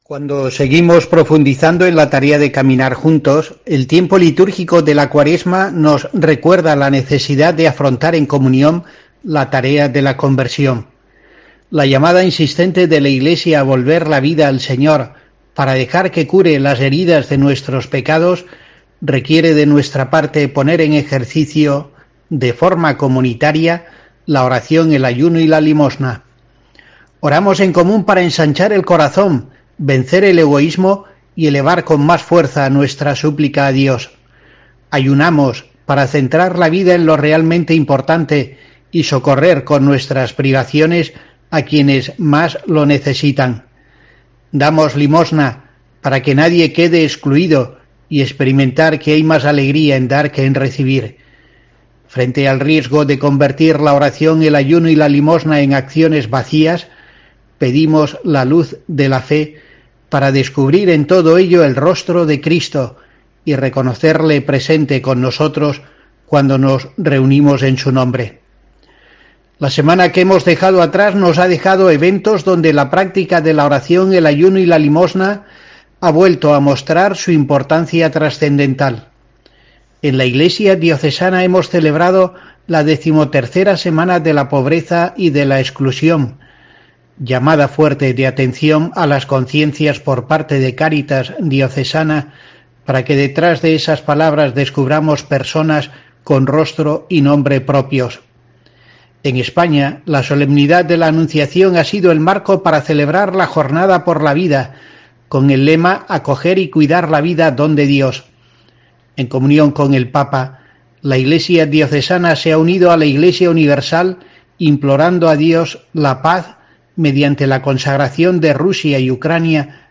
La llamada a la conversión que, de modo especial, se realiza cada Cuaresma preside el contenido del comentario semanal de monseñor José Rico Pavés para la programación religiosa de COPE.